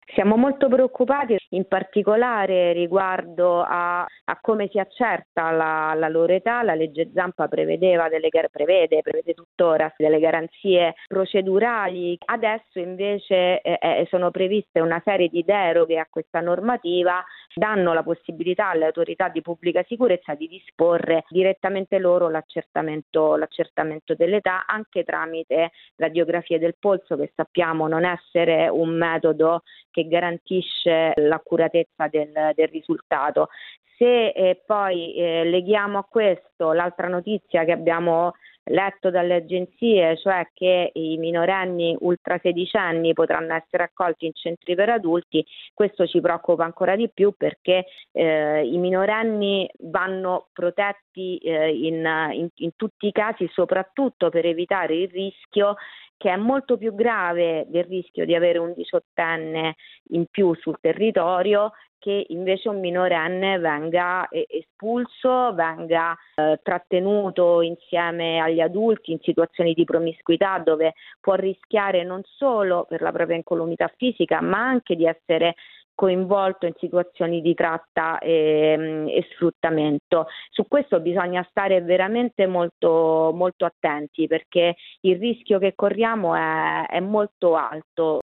come spiega in questo commento a caldo che abbiamo raccolto poco fa